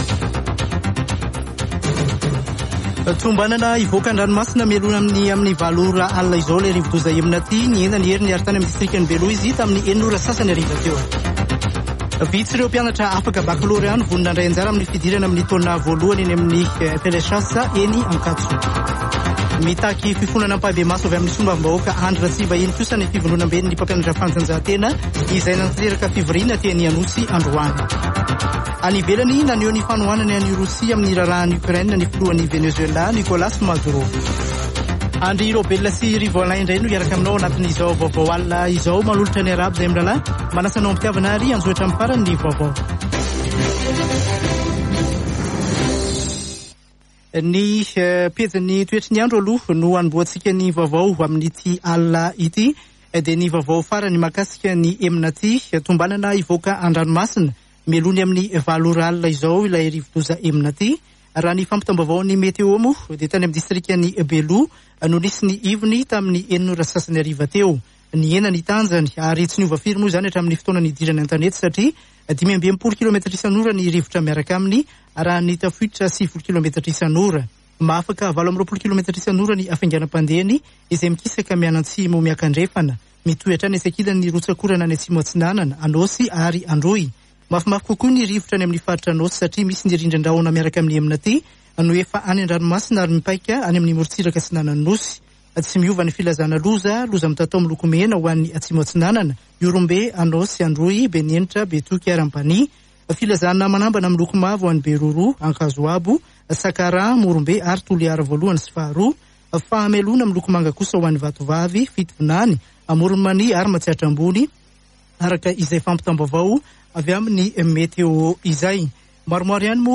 [Vaovao hariva] Alarobia 23 febroary 2022